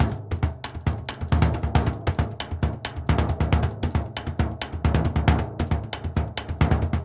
Bombo_Merengue 136_2.wav